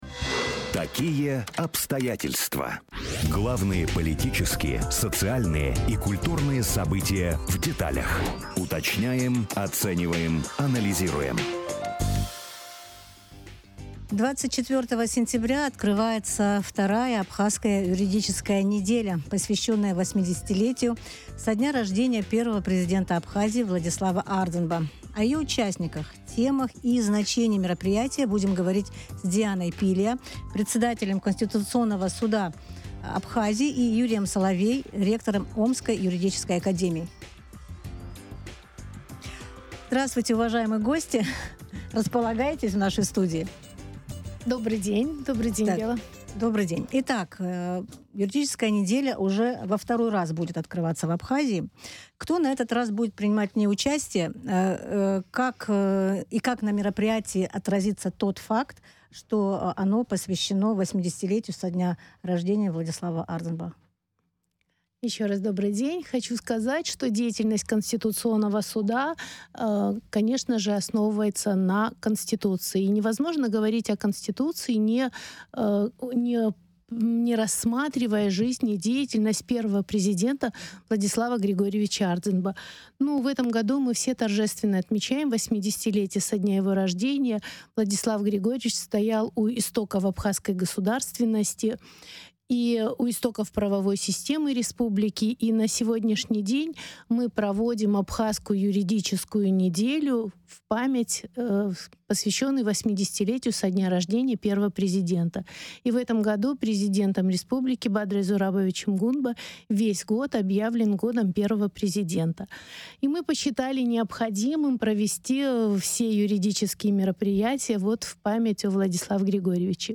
Абхазская юридическая неделя: интервью с участниками